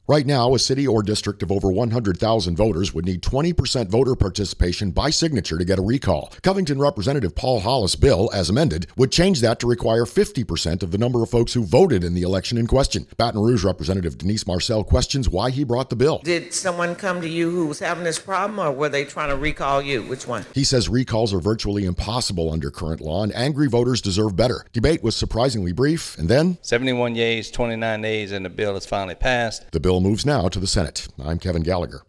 The Louisiana House has passed a bill that would lower the threshold of signatures required in order to trigger a recall election. Bill sponsor and Covington Representative Paul Hollis took questions from Baton Rouge Rep. Denise Marcelle about the numbers of registered voters that would be required to sign a recall petition, based on city, parish, or district population.